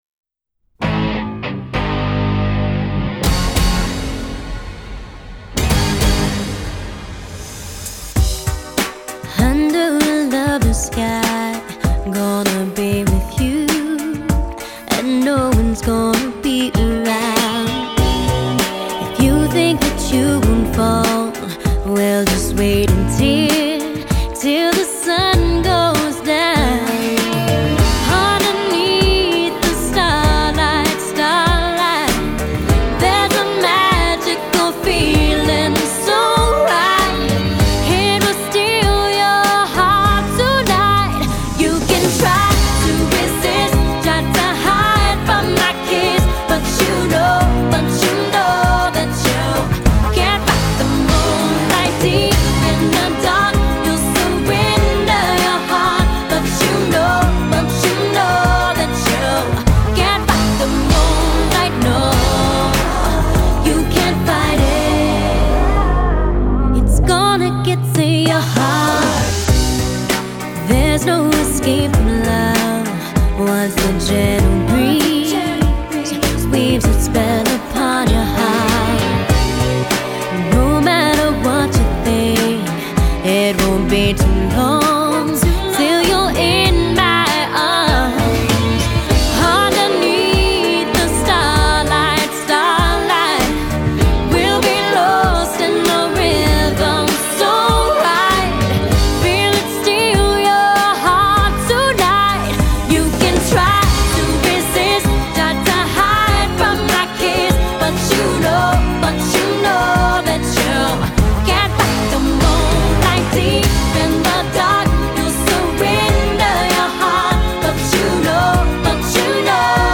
Назад в (pop)...